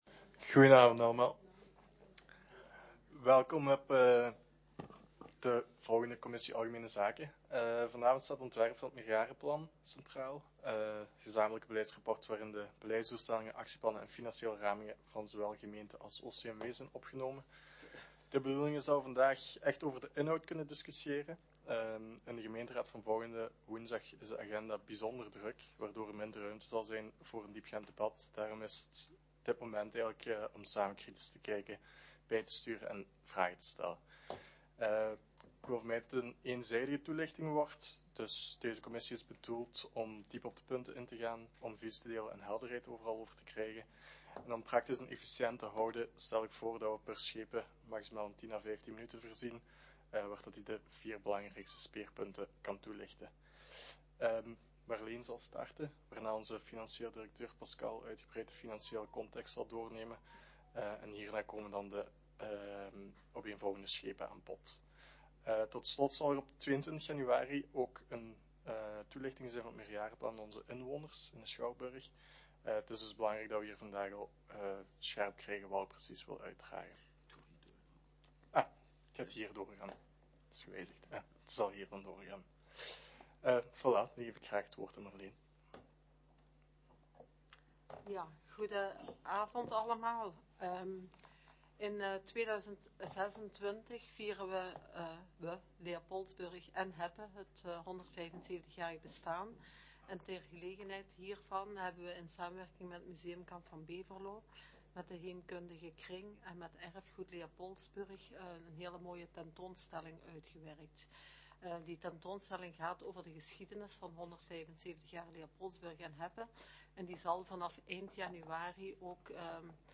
Opname commissie algemene zaken over het meerjarenplan 2026-2031 • 3 december 2025